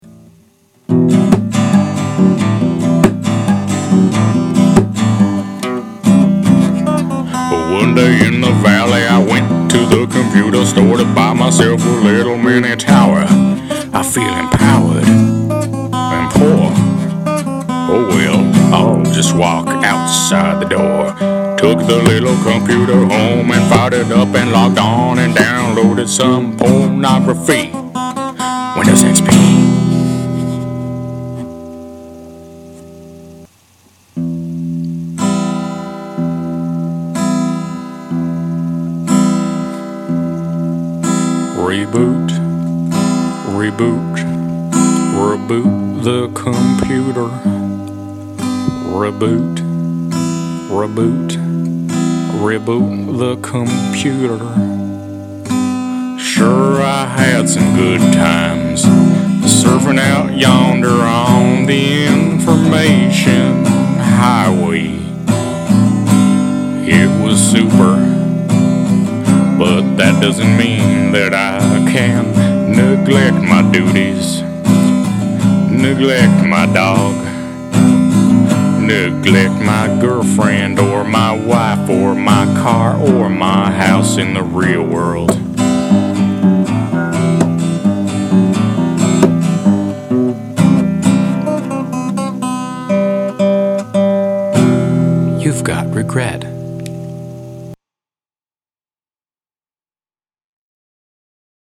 The Computer – Made up on the spot, this delightful song is about a man and his computer.